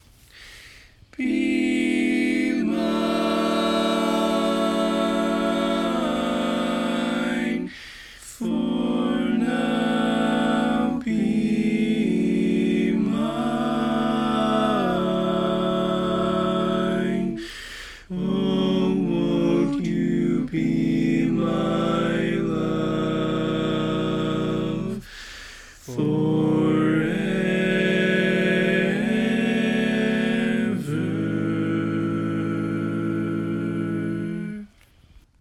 Key written in: A Major
Type: Barbershop